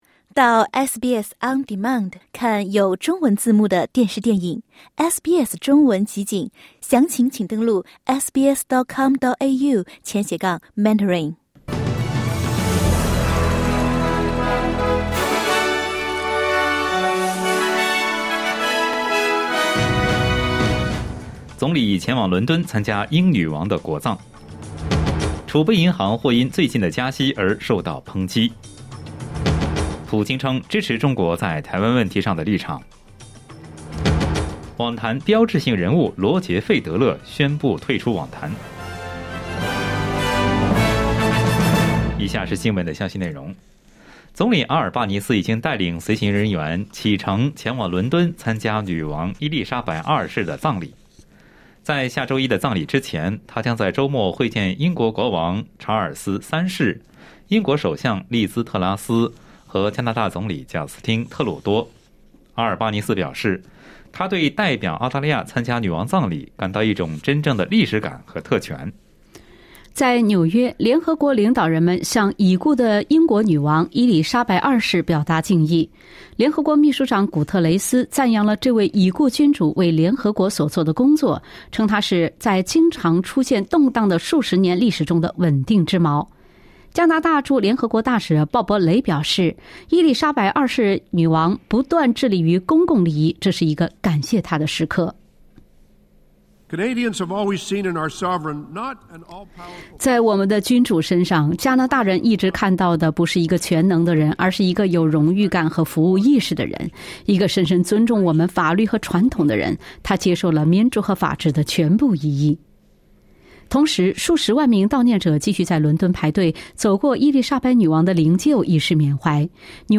SBS早新闻（9月16日）